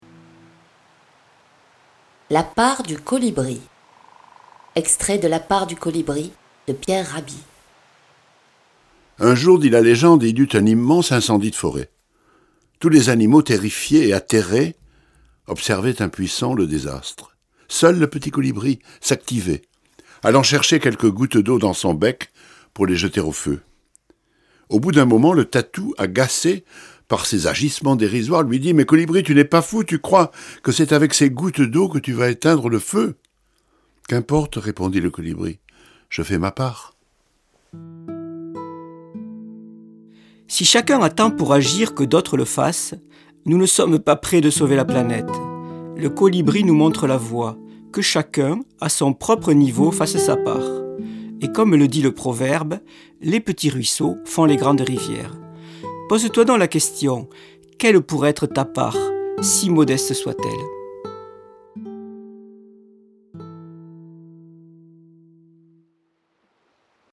Diffusion distribution ebook et livre audio - Catalogue livres numériques
Philosophie / Conte / Fable